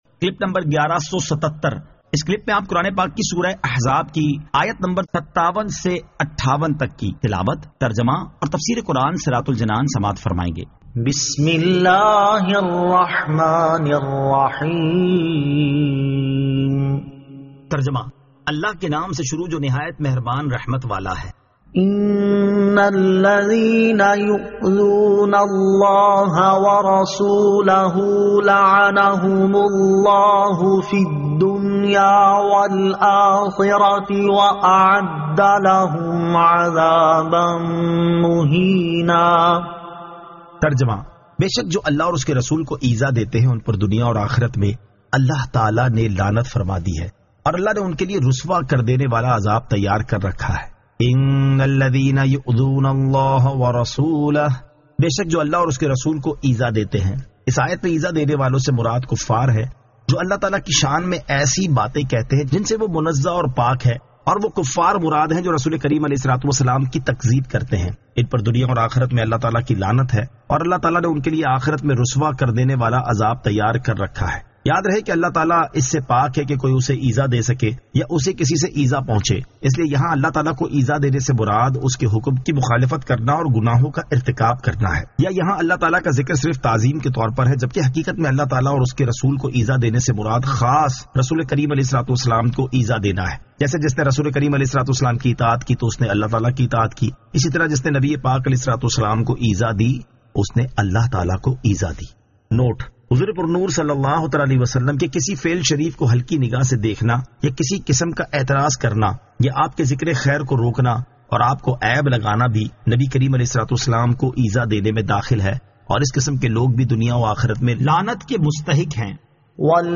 Surah Al-Ahzab 57 To 58 Tilawat , Tarjama , Tafseer